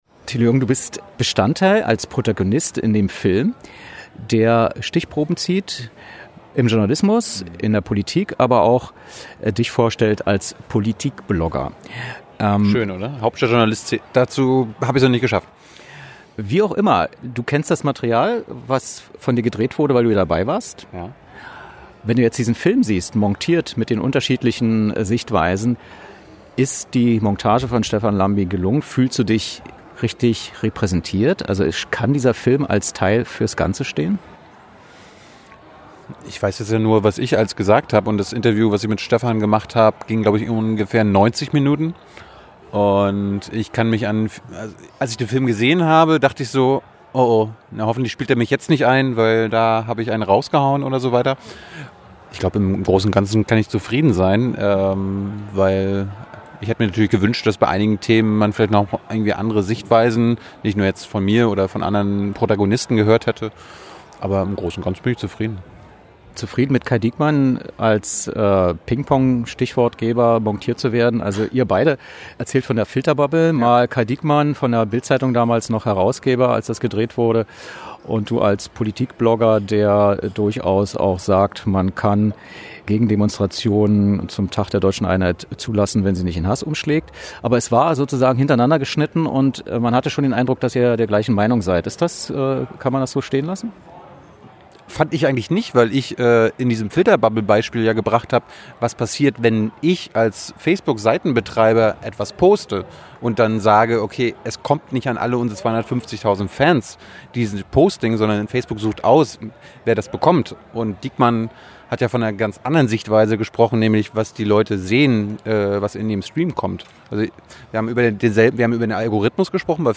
Was: Audio-Interview zum Dok-Film „Nervöse Republik“
Wer: Sahra Wagenknecht, Die Linke, Fraktionsvorsitzende
Wo: Berlin, Kino Babylon